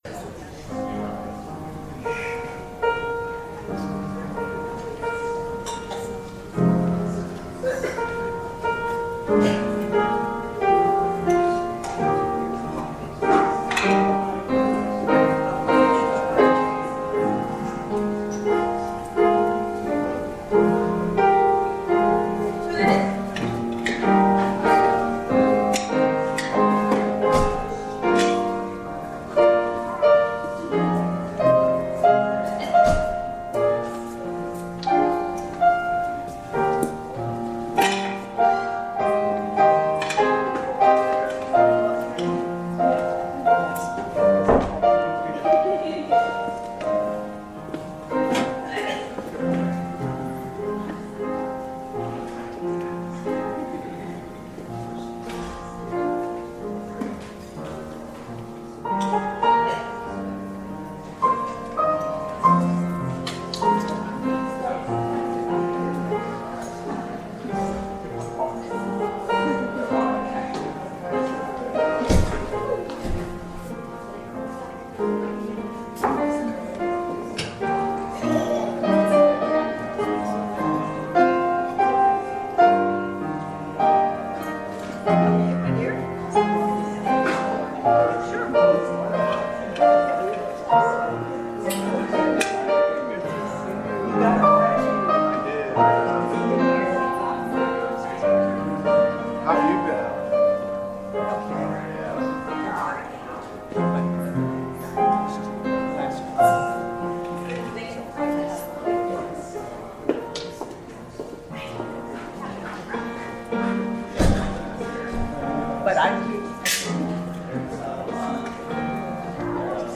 Audio recording of the 10am hybrid/streamed service (in the Parish Hall), sermon
We have been worshiping in the Parish Hall, which doesn’t have the same recording capabilities.